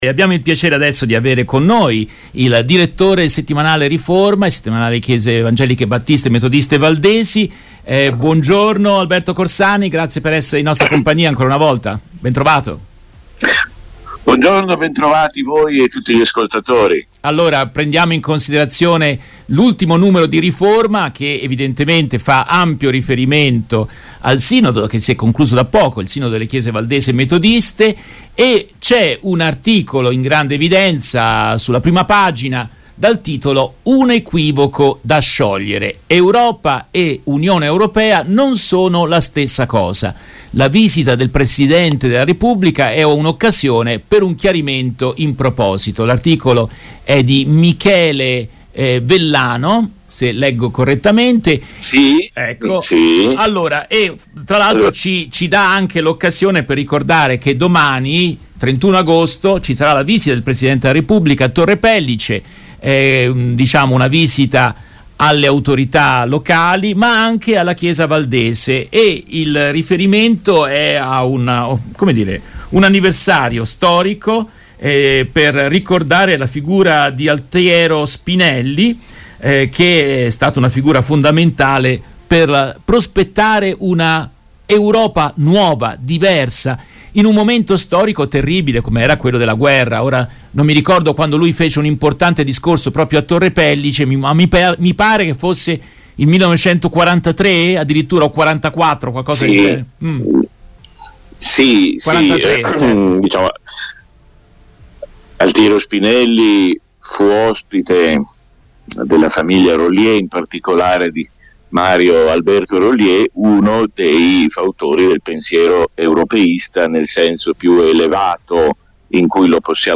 Continuano le conversazioni